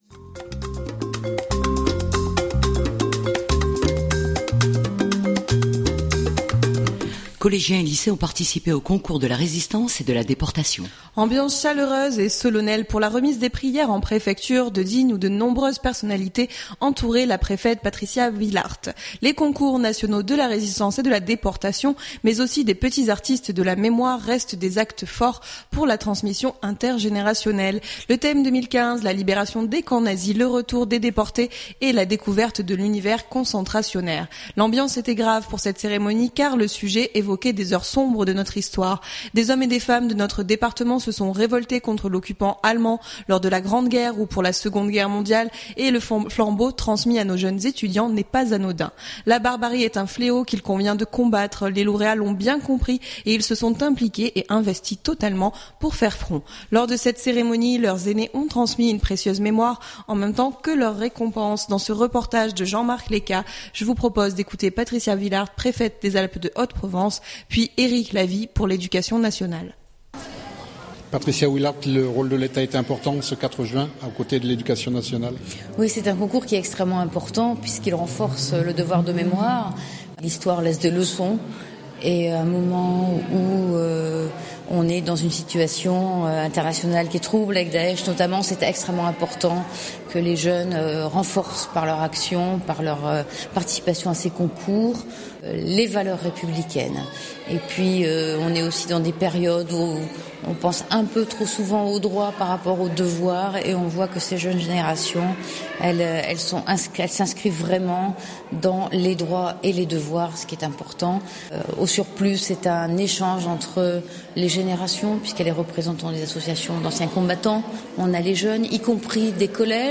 Ambiance chaleureuse et solennelle pour la remise des prix hier en Préfecture où de nombreuses personnalités entouraient la Préfète Patricia Willaert.